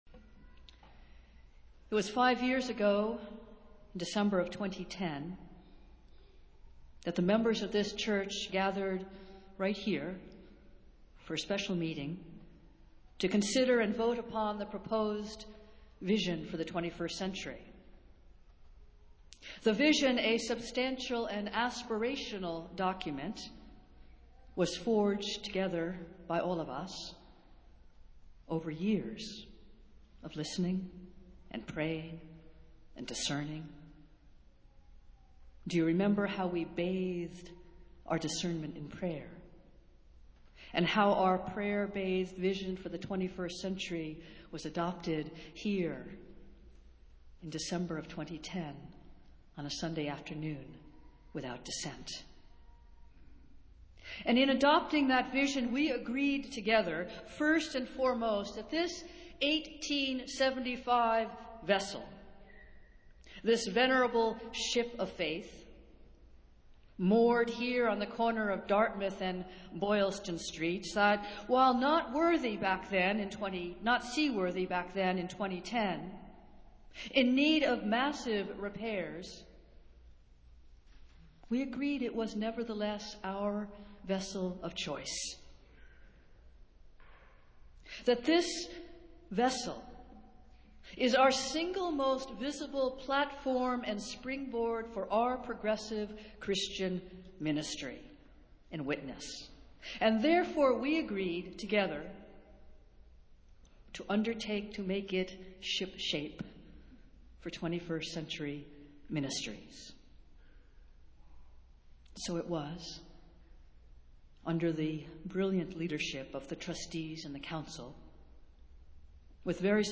Festival Worship - 345th Annual Meeting Sunday